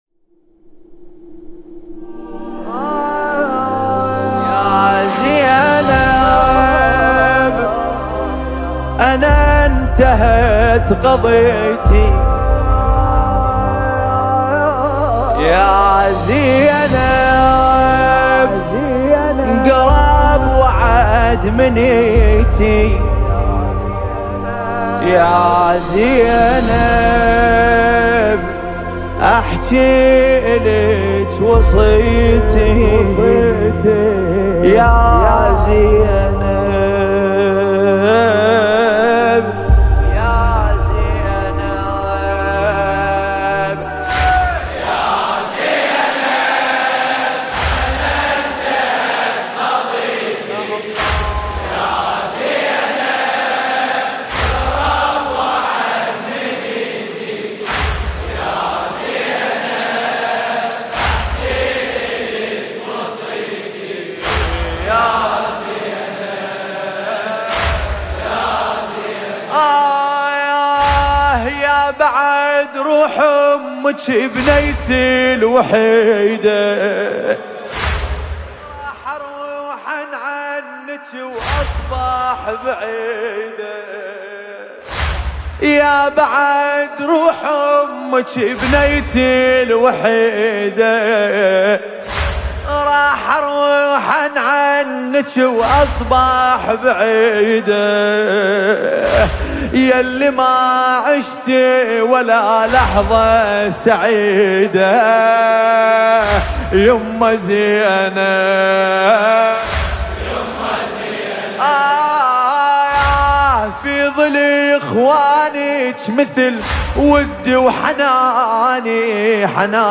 نوحه عربی دلنشین